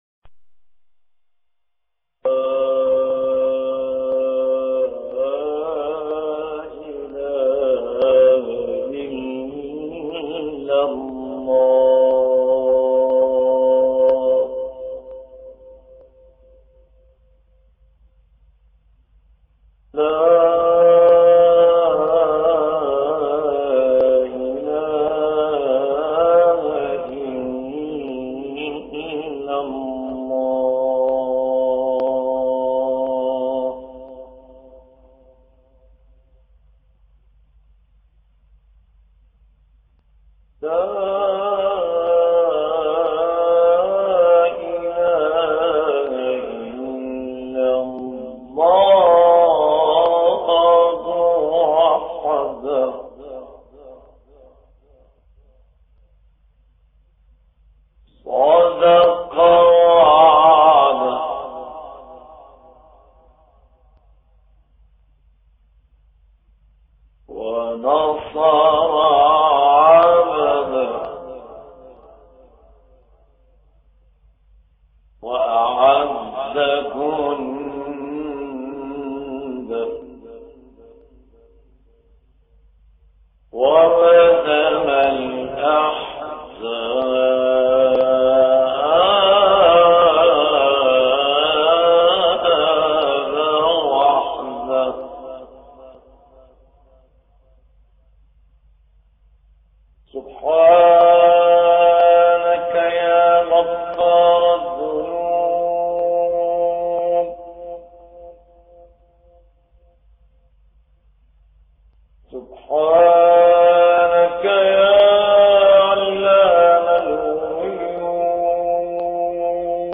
ابتهال الشيخ نصر الدين طوبار : لا اله الا الله وحده لا شريك له